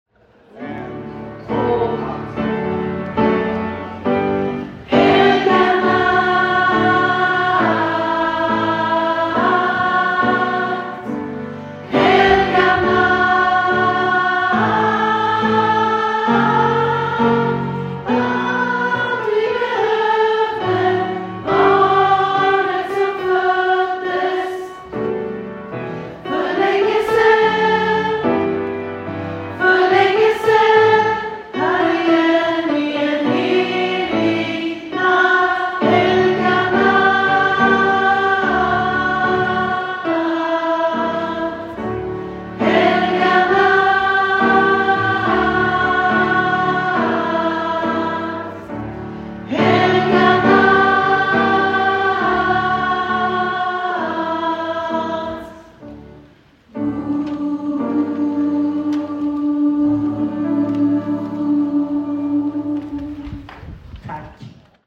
Helga_natt-Alt-(Storkor).mp3